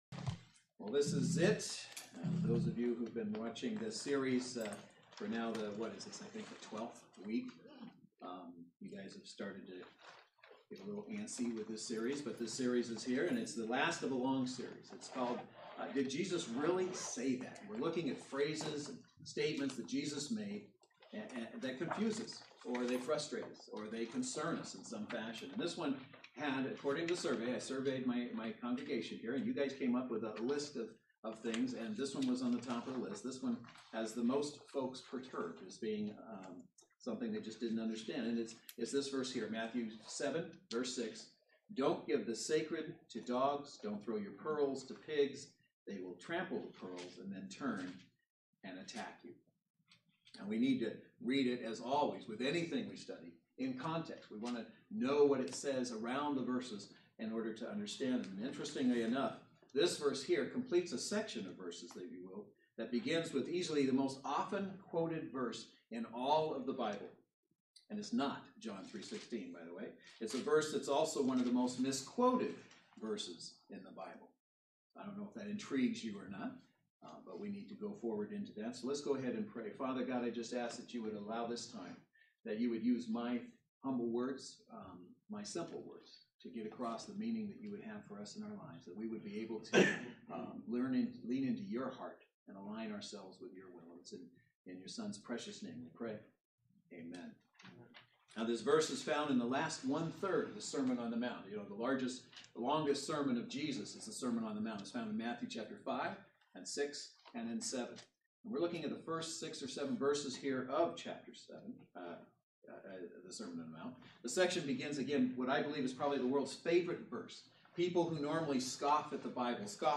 Matthew 7:6 Service Type: Saturday Worship Service Bible Text